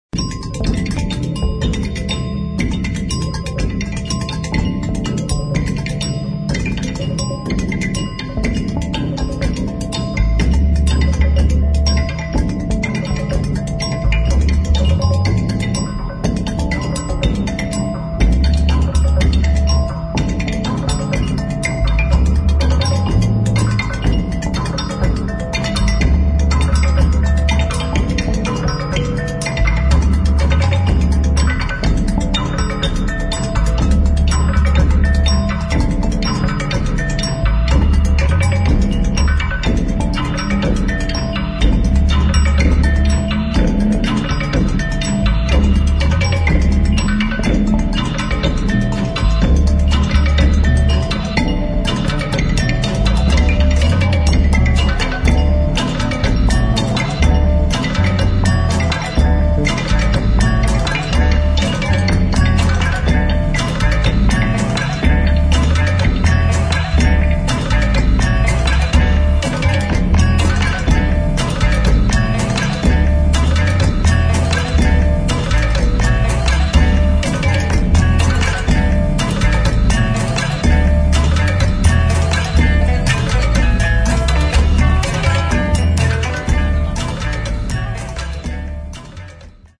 [ HOUSE / BASS / EXPERIMENTAL ]